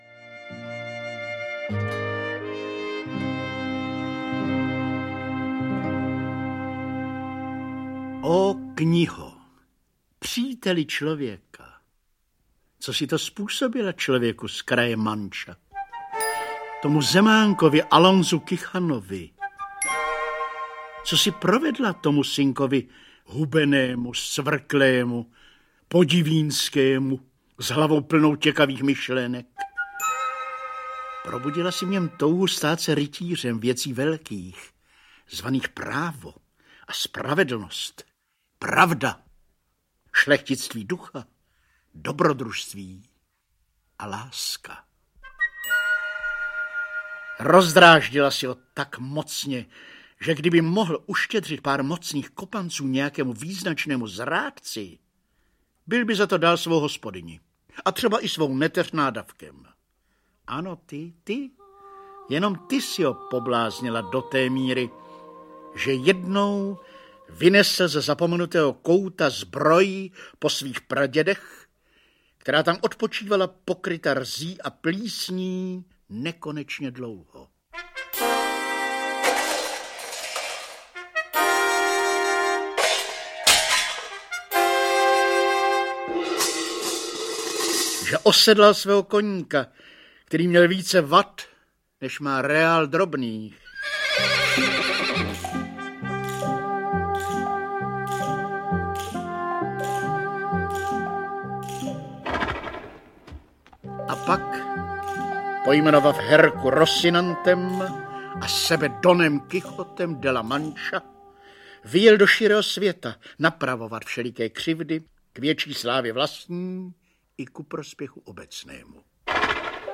Audio kniha
Ukázka z knihy
Rozhlasová verze slavného románu z roku 1988.